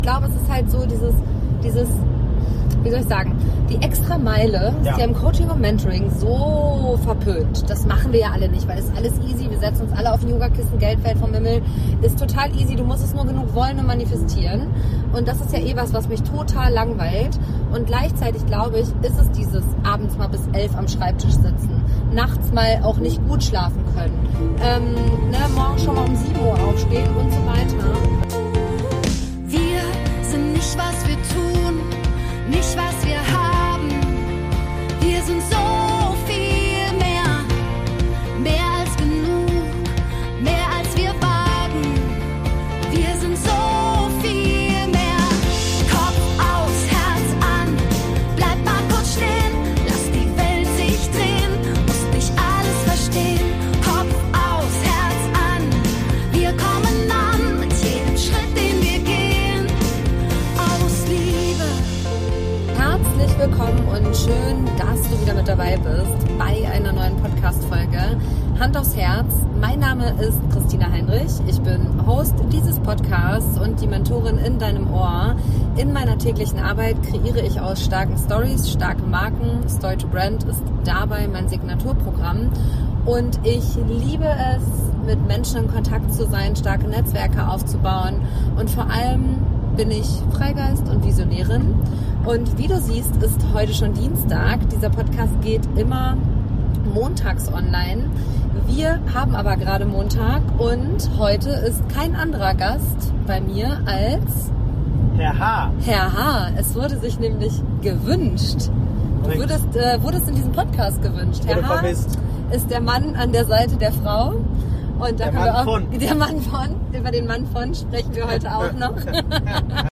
Kein Studio, kein Skript, sondern Gedanken, die unterwegs entstehen, wenn man das Jahr nochmal bewusst an sich vorbeiziehen lässt.